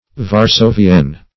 Search Result for " varsovienne" : The Collaborative International Dictionary of English v.0.48: Varsovienne \Var`so`vienne"\, n. [F., prop. fem. of varsovien pertaining to Warsaw, fr. Varsovie Warsaw, Pol.